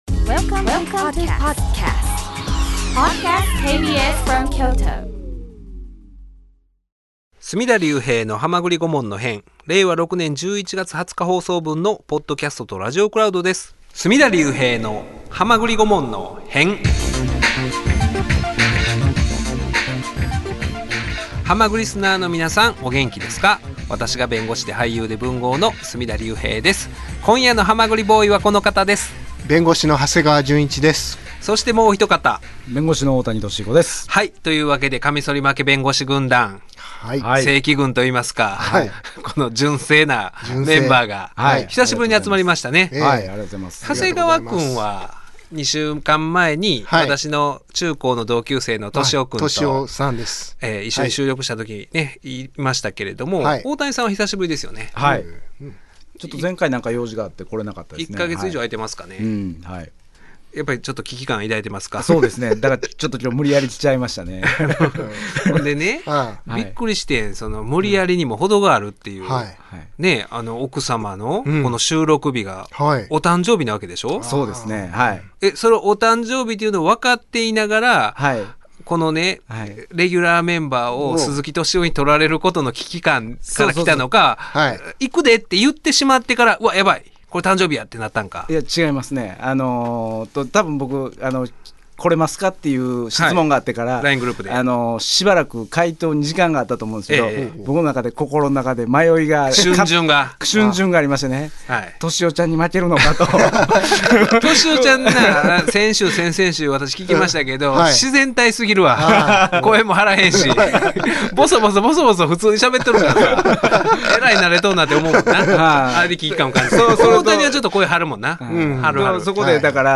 【KBS京都ラジオ 水曜日 19:30～21:00 オンエア】お騒がせのニュースやスキャンダル、日常のささいな出来事も法律目線でとらえることで、より深くより興味深い案件に大変身！